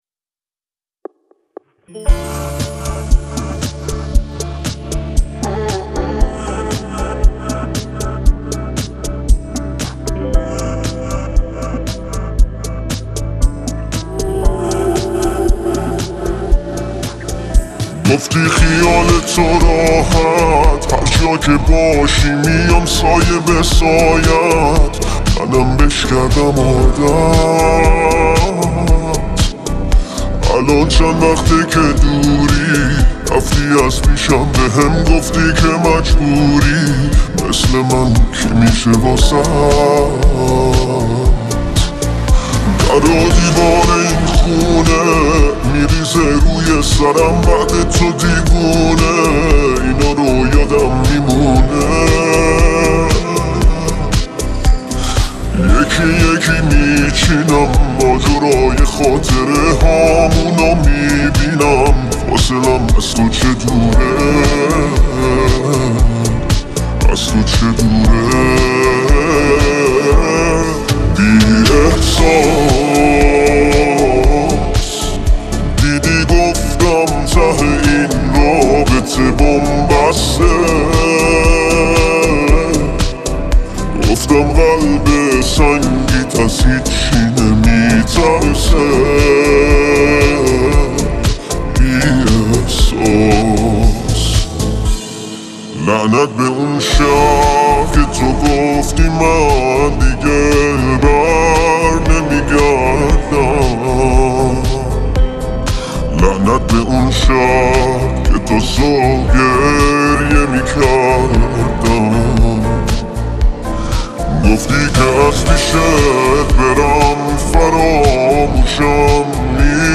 با صدای دیگر ایجاد کرده‌ایم